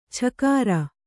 ♪ chakāra